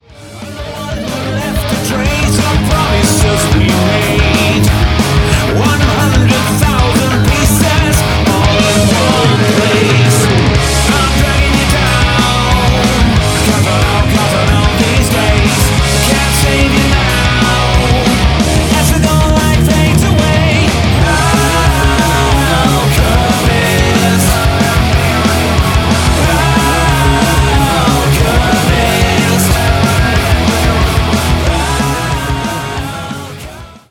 RETRO MODERNIST ALT-METAL